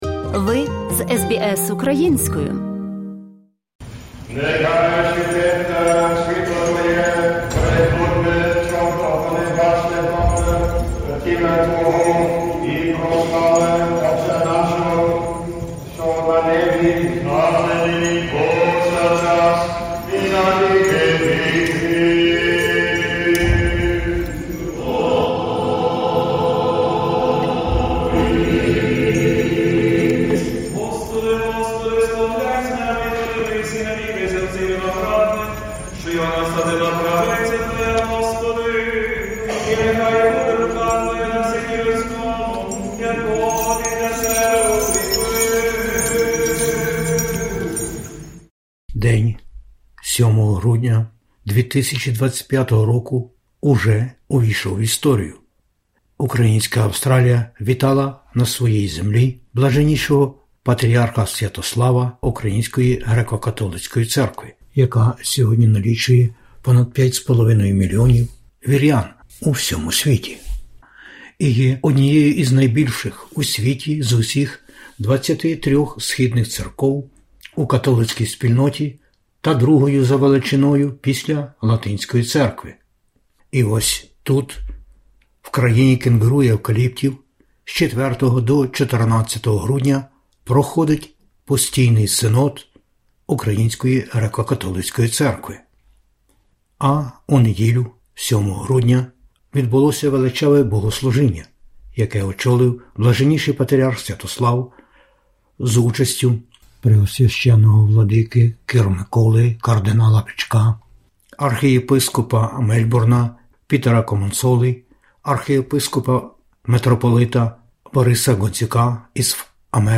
Ukrainian Catholic Cathedral of Saints Peter and Paul, Melbourne. Hierarchical Divine Liturgy celebrated by His Beatitude Sviatoslav Shevchuk (Блаженніший Патріярх Святослав, Отець і Глава УГКЦ), Father and Head of the Ukrainian Greek Catholic Church (UGCC).